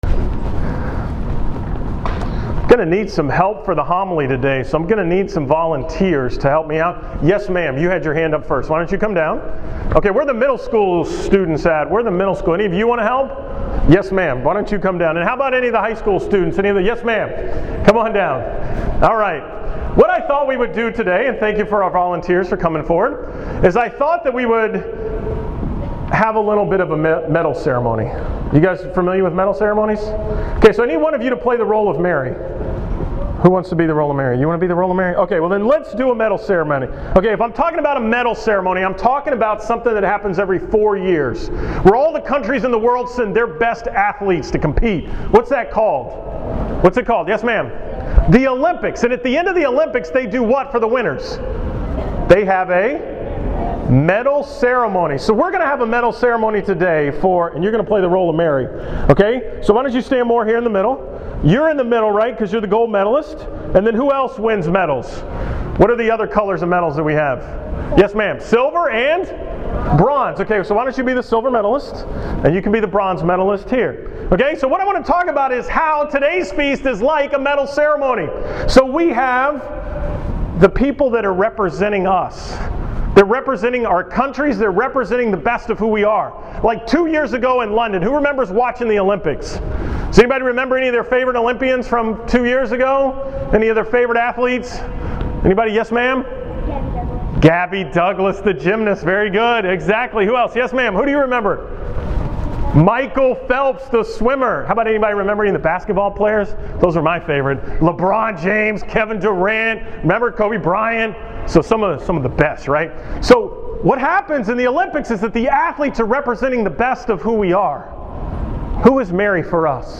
The Feast of the Assumption of Mary: August 15, 2014At Duchesne all school Mass